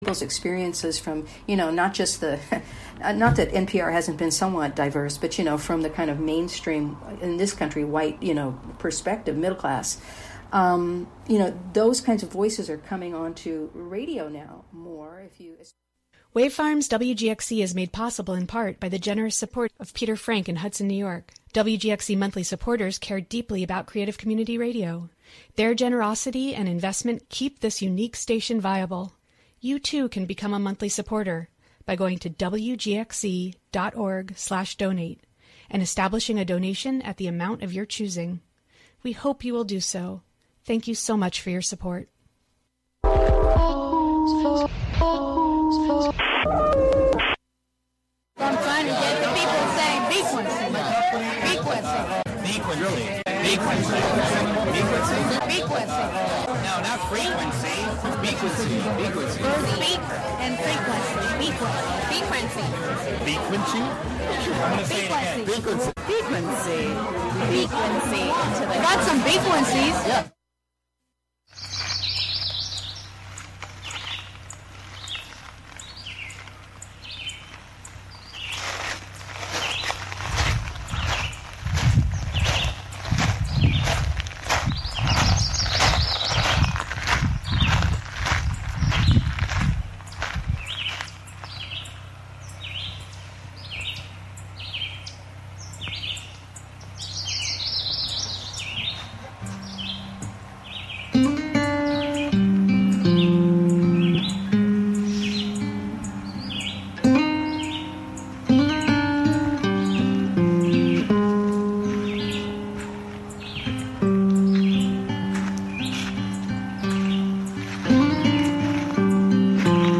--- "Beakuency" invites the local community to be inspired by the joy, beauty, and wisdom bird enthusiasts find in the nature of our neighborhoods. Every episode features an interview with local bird people, plus a freeform mix of sound made by birds and humans inspired by birds, and “Birds of Wave Farm,” a field recording journal from Wave Farm, in Acra, New York.